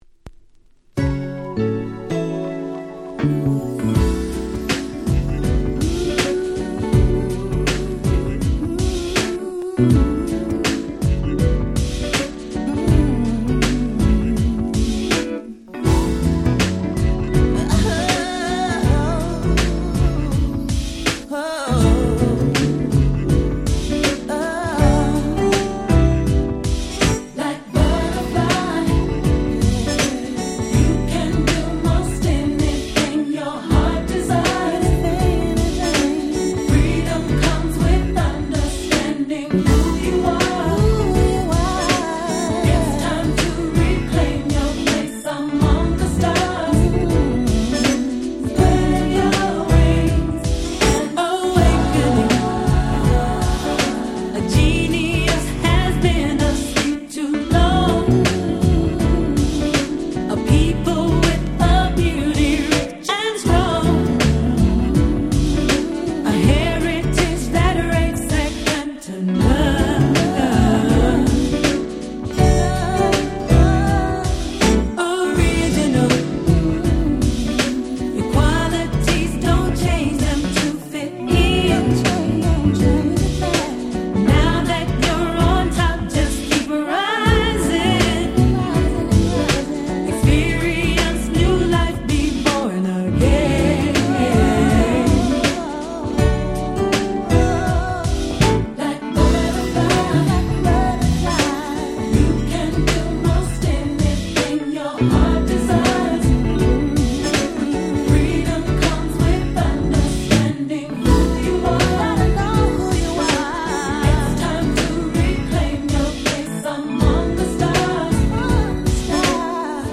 94' Nice R&B EP !!